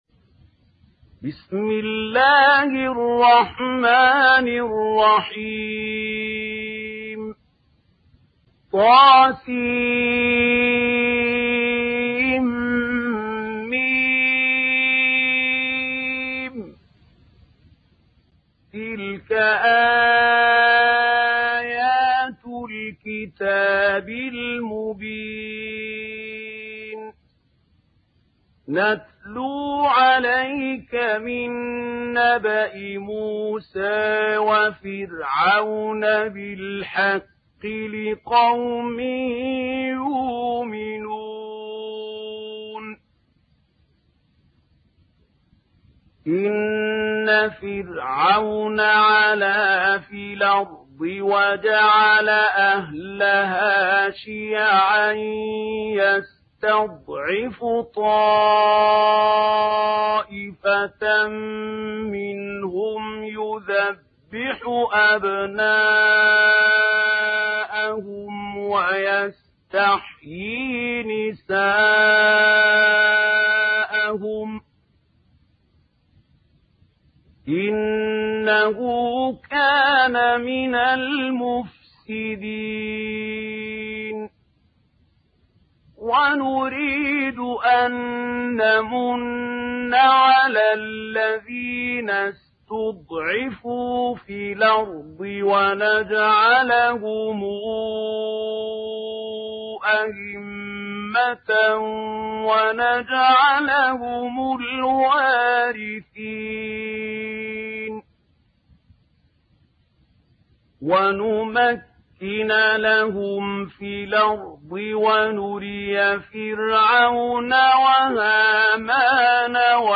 Kasas Suresi mp3 İndir Mahmoud Khalil Al Hussary (Riwayat Warsh)